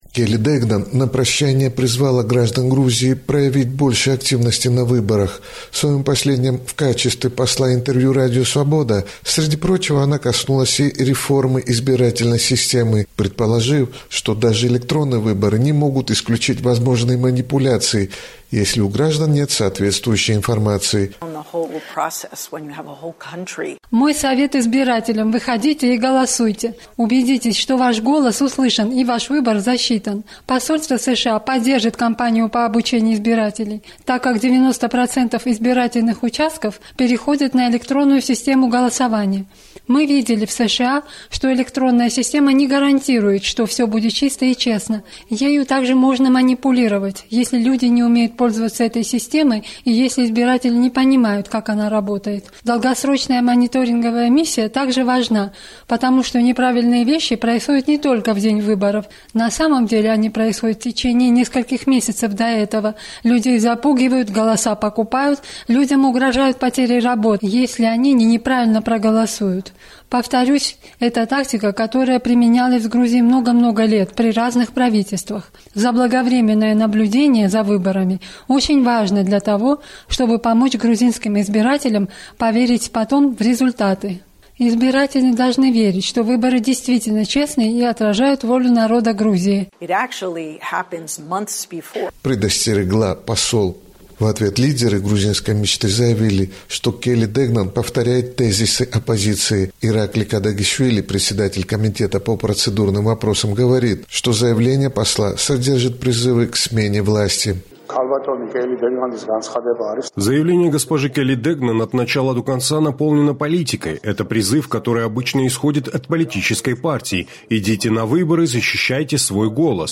Прощальное интервью посла США Келли Дегнан, завершившей свою дипломатическую миссию в Грузии, привело к политическому скандалу. За год до парламентских выборов посол предостерегла грузинскую власть от соблазна манипуляции: выборы в Грузии могут быть нечестными, если у власти вновь останется возможность манипулировать голосами, и электронное голосование не решит проблему прозрачности выборов.